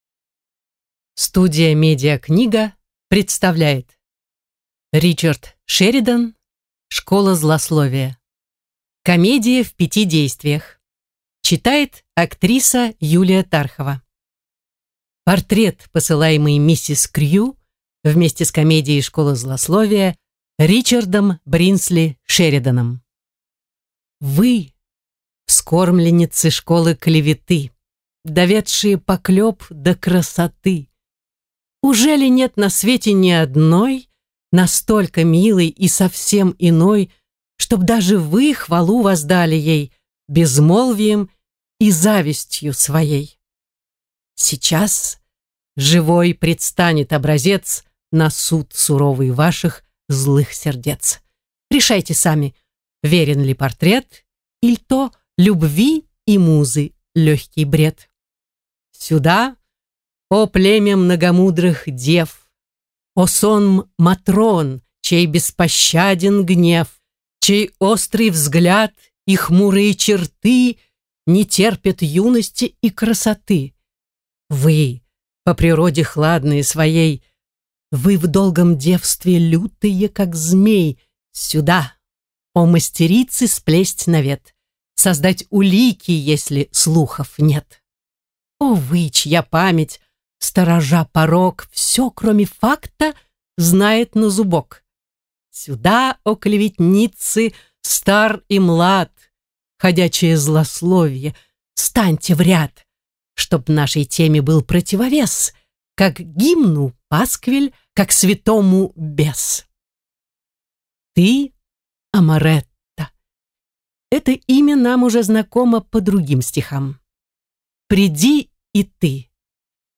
Аудиокнига Школа злословия | Библиотека аудиокниг